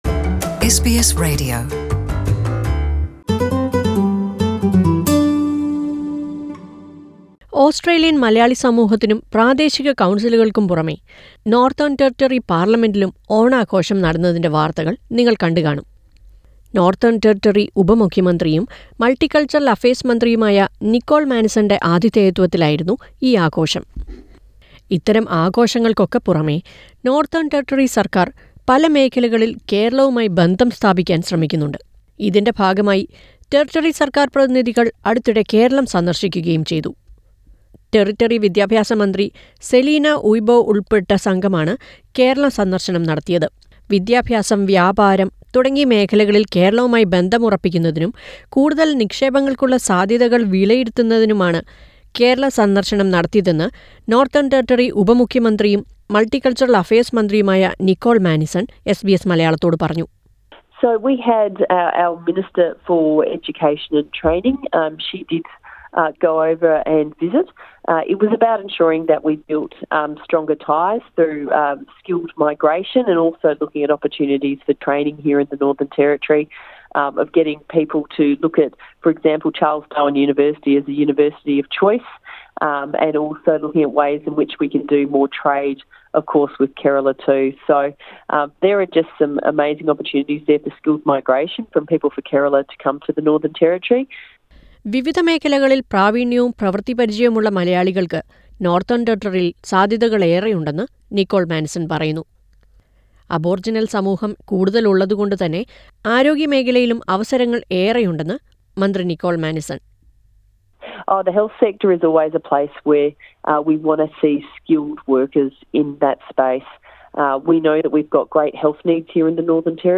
As part of building relationship with Kerala, a team led by NT Education Minister Selina Uibo visited Kerala. Listen to NT Deputy Chief Minister and Multicultural Affairs Minister Nicole Manison who speaks about it to SBS Malayalam.